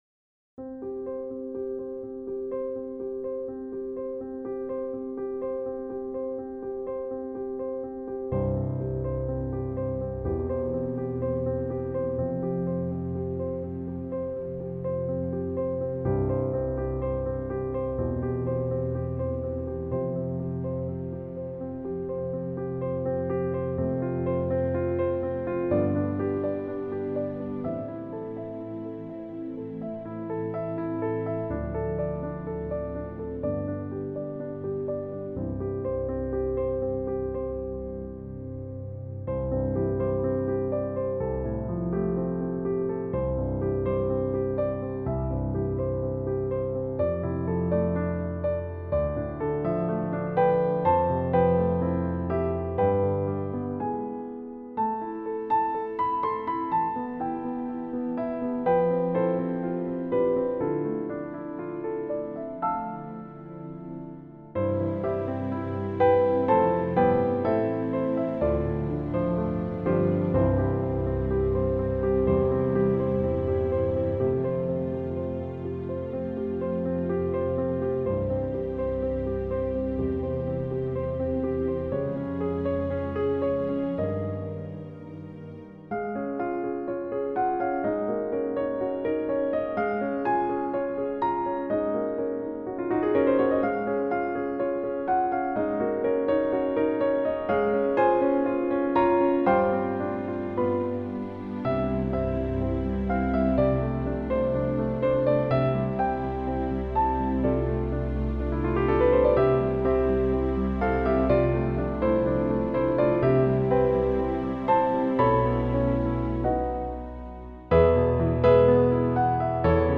A service for 14th February 2021